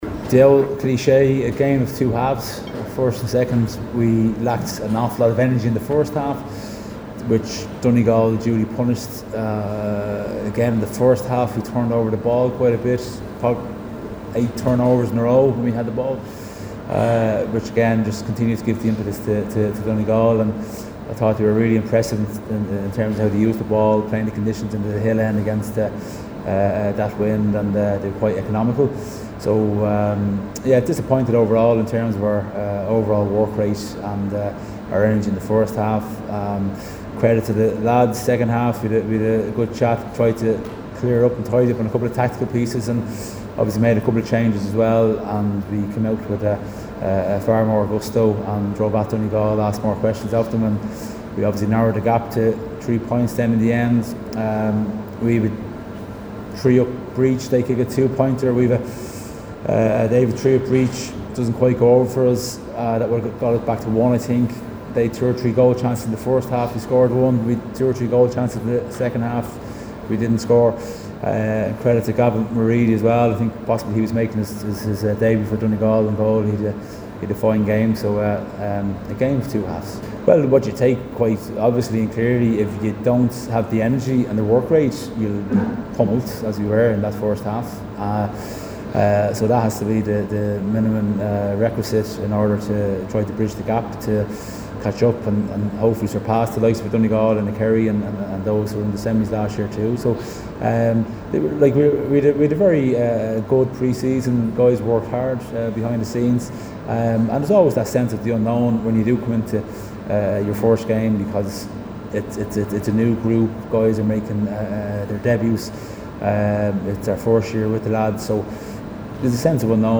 After the game, new Dubs boss Brennan said they lacked the energy needed in the opening period.